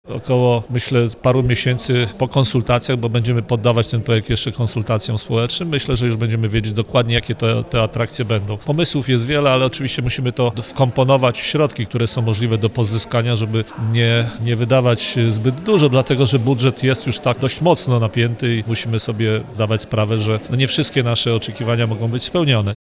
– Ostateczny wygląd tego terenu będzie znany po zakończeniu etapu projektowania – mówi burmistrz Wiesław Ordon.